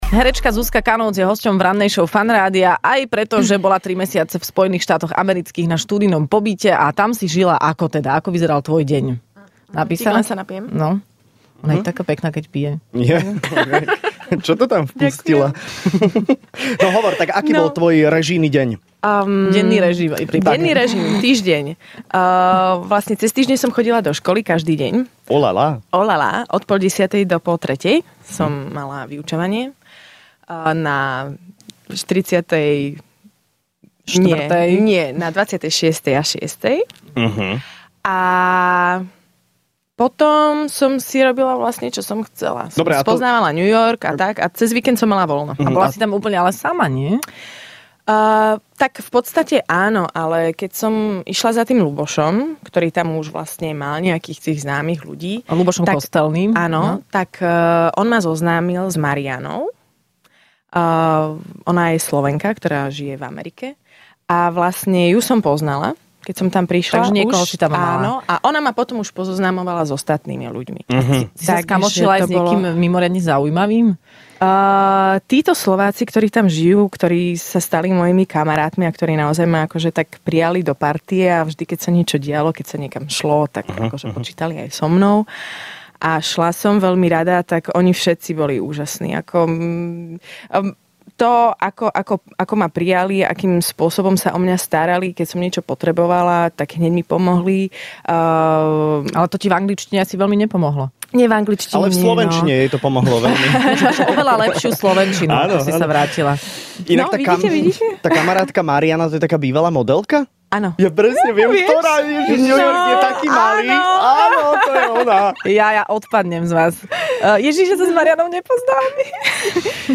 Hosťom v Rannej šou bola herečka Zuzka Kanócz, ktorú poznáte zo seriálu Ordinácia v ružovej záhrade ale aj z rôznych divadelných predstavení.